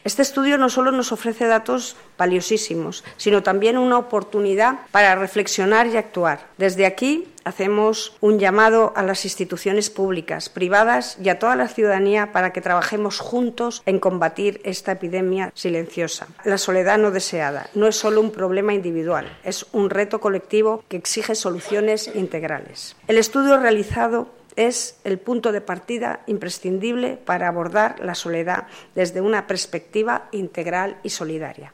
Corte2-Diputada-soledad.mp3